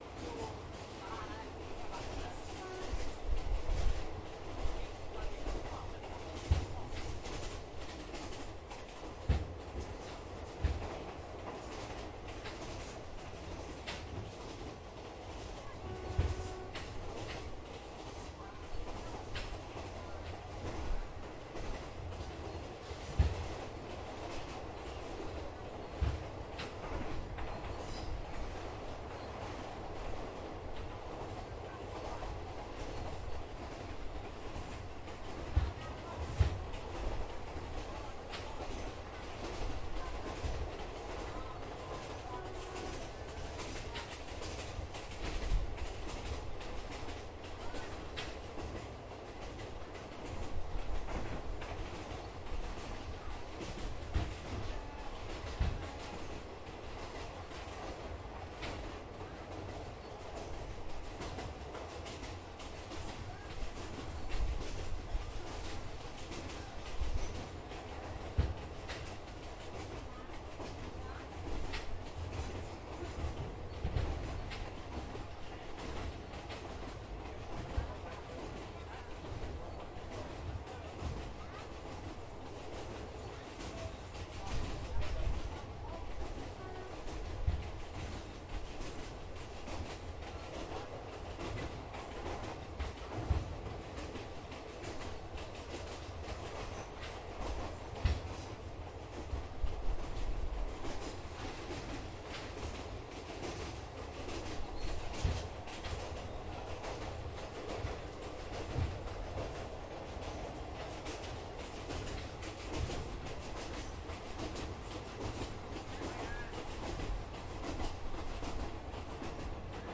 描述：在火车上。记录了2013年11月28日，下午4点10分。躺在座位上的记录器.Tascam DR40，内置麦克风，Stéréo。
Tag: 机车 车轮 火车 铁路 振动 乘客列车